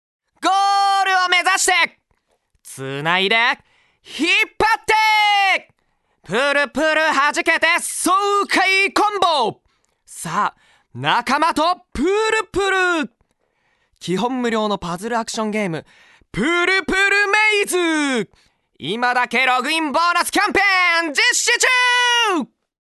ナレーション2(CM風）